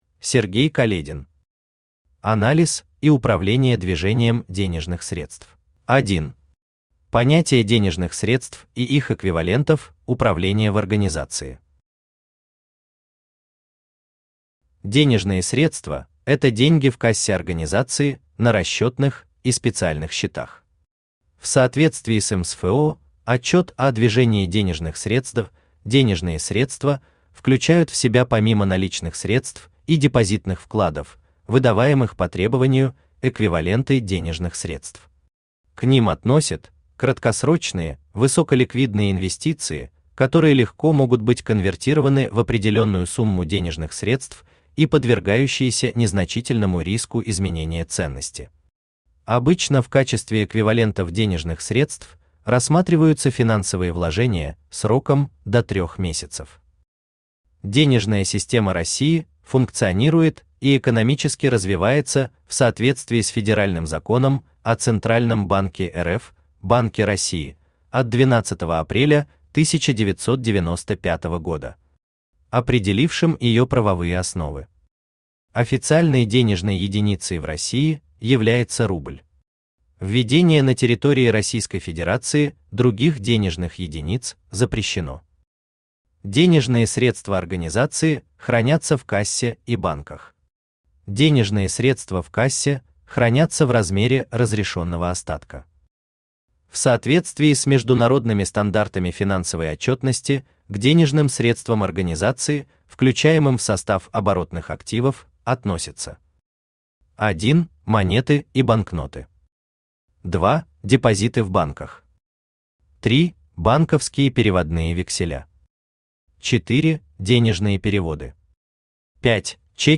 Аудиокнига Анализ и управление движением денежных средств | Библиотека аудиокниг
Aудиокнига Анализ и управление движением денежных средств Автор Сергей Каледин Читает аудиокнигу Авточтец ЛитРес.